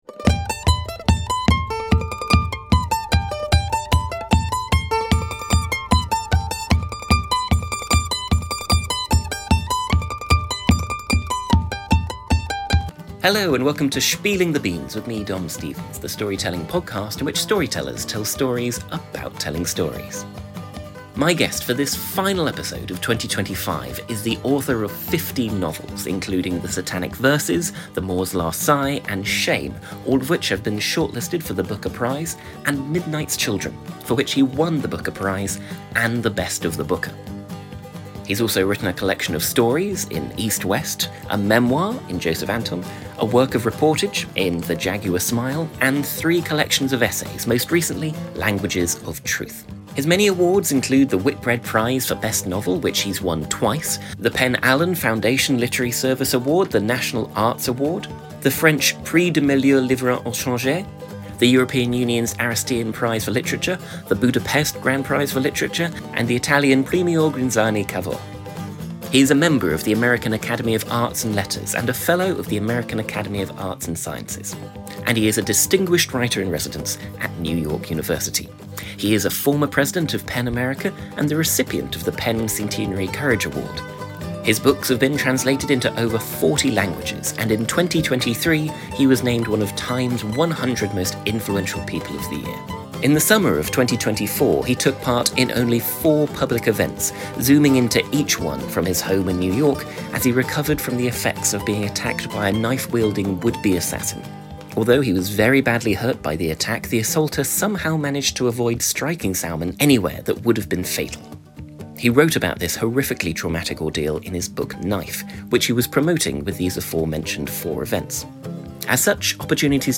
For the finale of season seven, my guest is the Booker Prize winning author Sir Salman Rushdie. Recorded at the Edinburgh International Book Festival 2024.